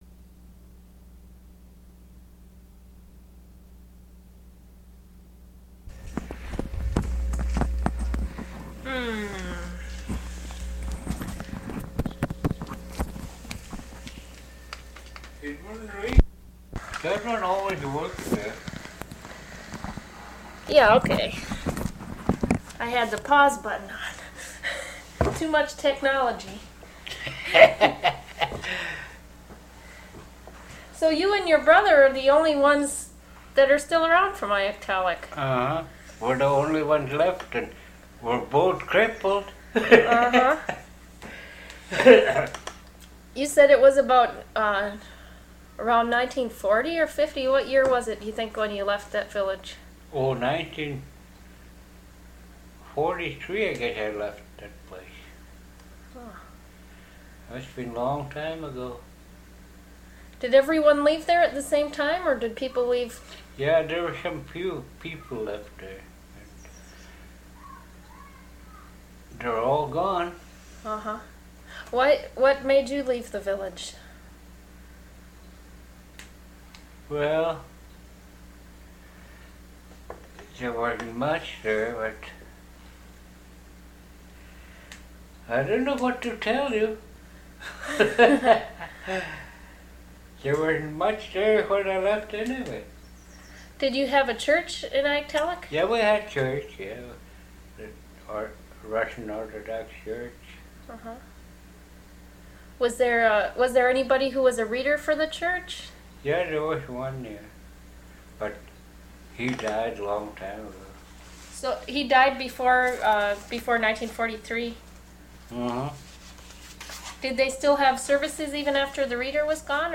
Quality: good. (In English) Location: Location Description: Kodiak, Alaska